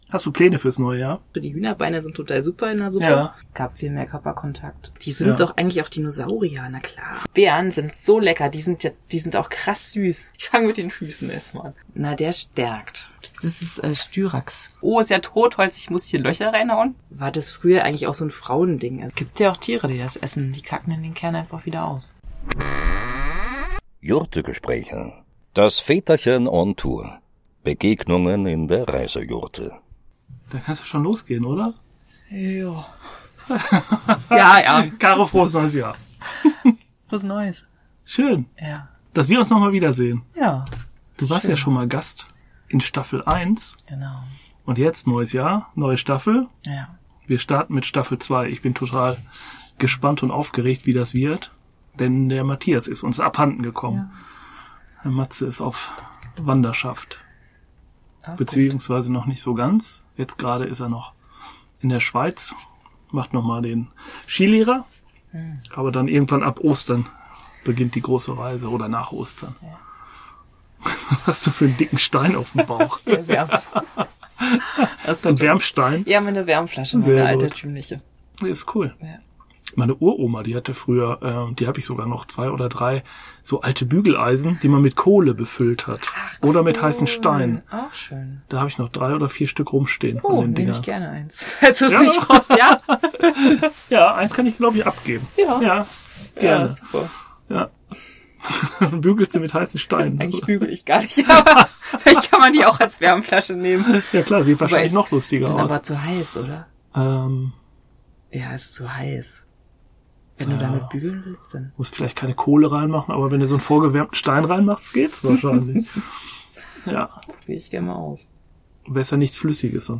Der Podcast aus der Jurte!